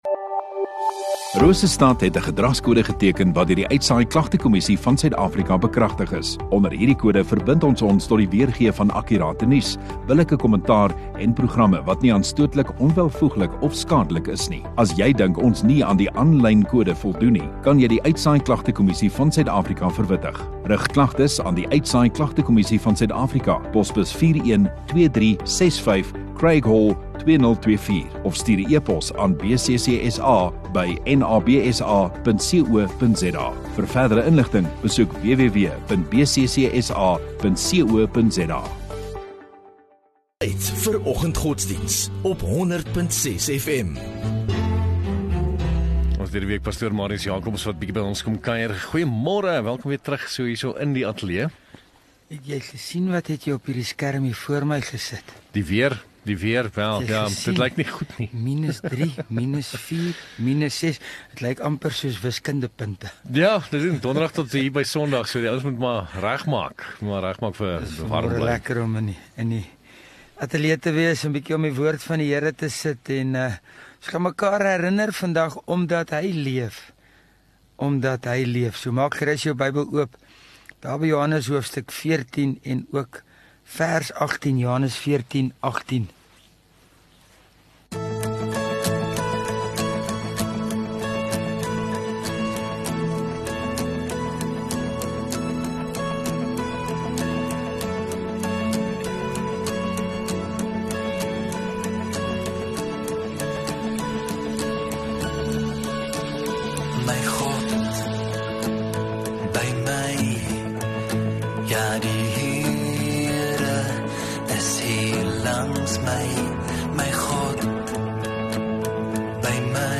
View Promo Continue Install Rosestad Godsdiens 2 Jul Dinsdag Oggenddiens